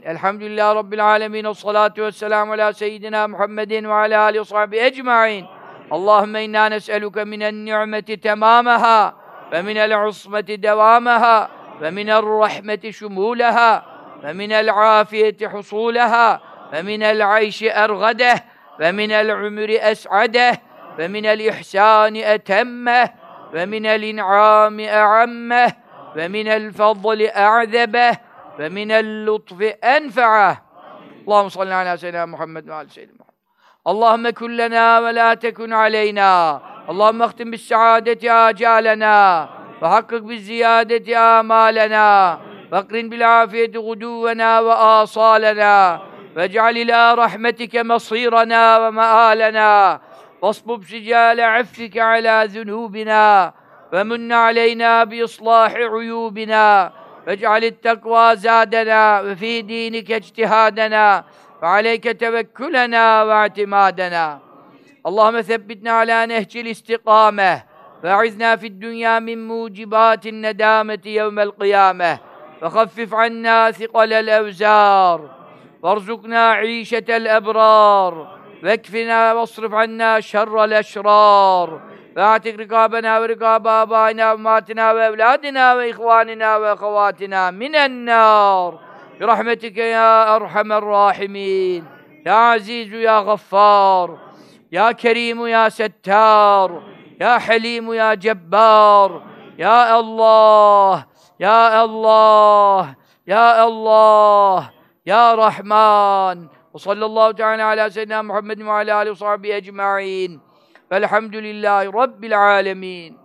Okunuşu
Cübbeli Ahmet Hoca okuyor